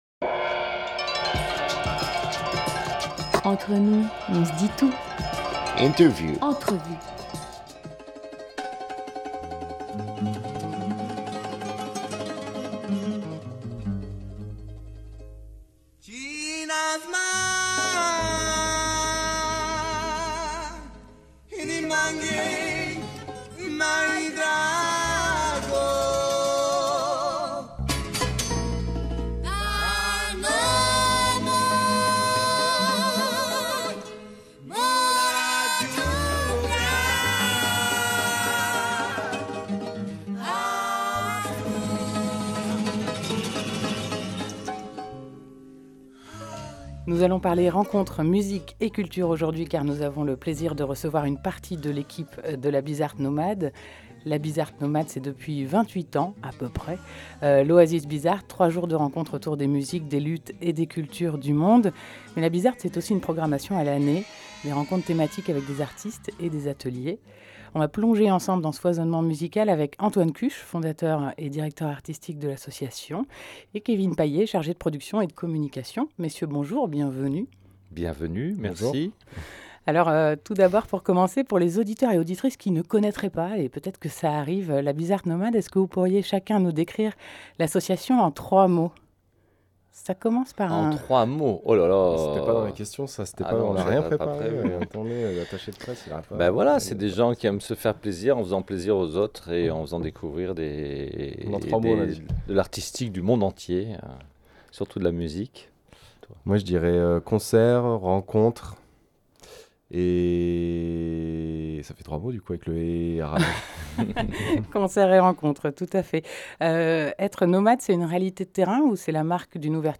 12 avril 2019 12:34 | Interview
ITW-BizzArt-Nomade_-avant-lOasis.mp3